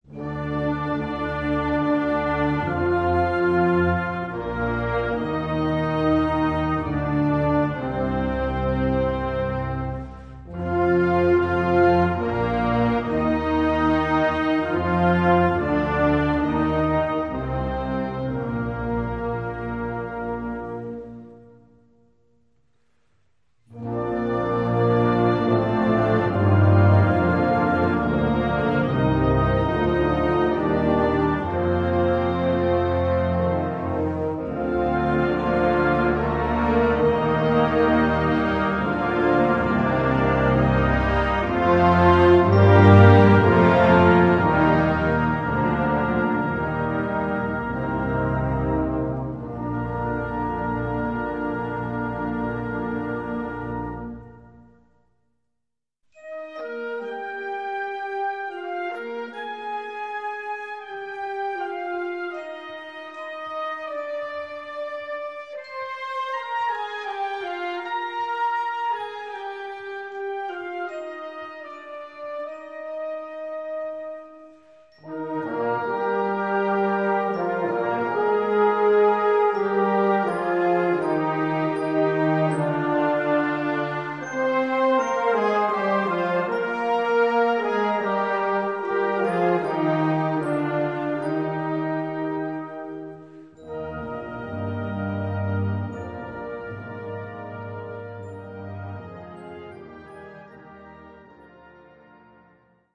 Gattung: Schulungswerk
Besetzung: Blasorchester